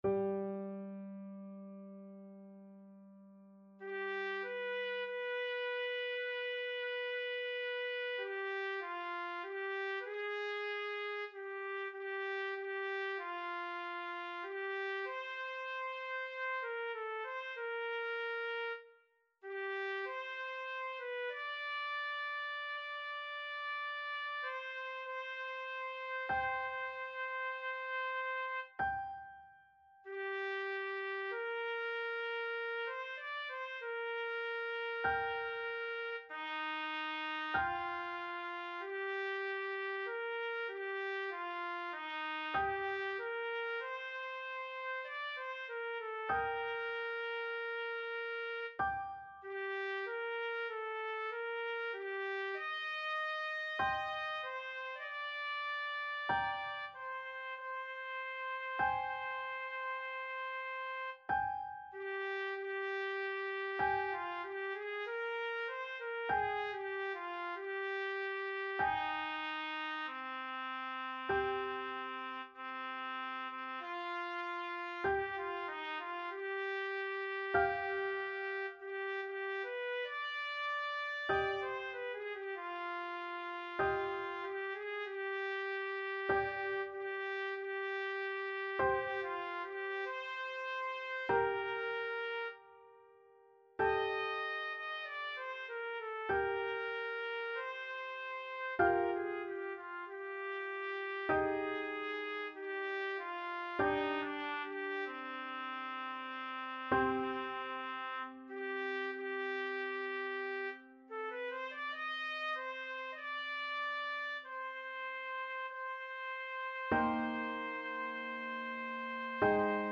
Trumpet version
Lent =48
Classical (View more Classical Trumpet Music)